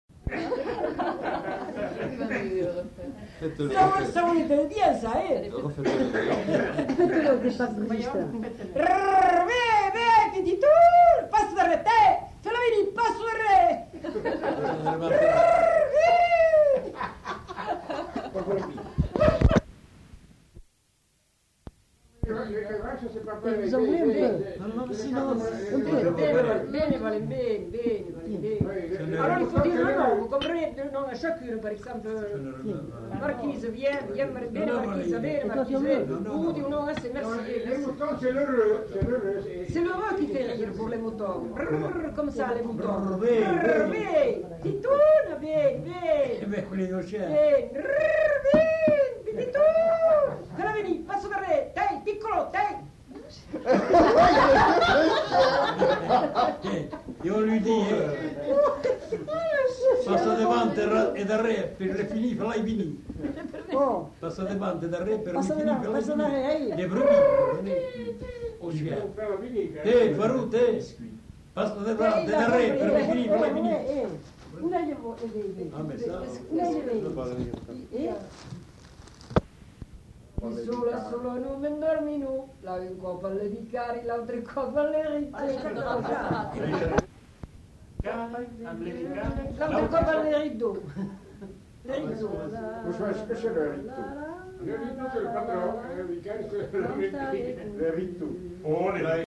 Appel au bétail
Lieu : Prat-Communal (lieu-dit)
Genre : expression vocale
Effectif : 1
Type de voix : voix de femme
Production du son : crié
Classification : appel au bétail